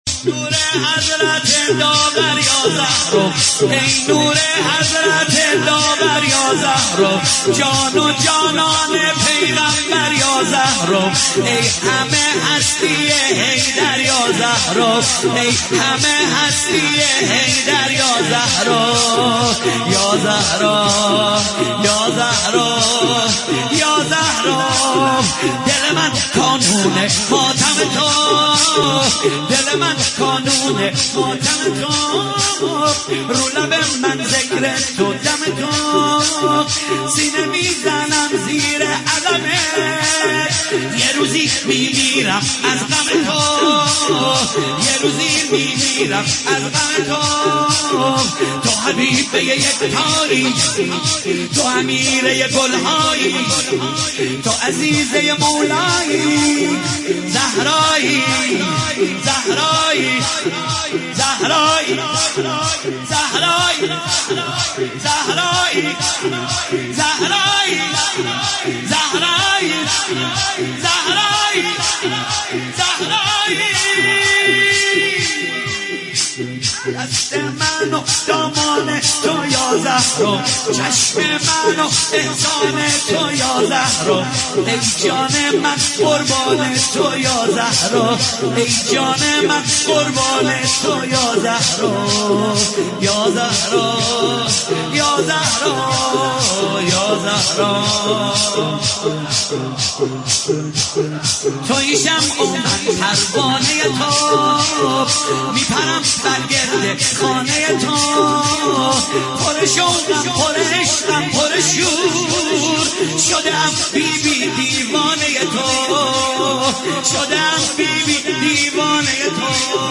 دانلود مداحی شهادت حضرت زهرا (س) شب اول فاطمیه بهمن96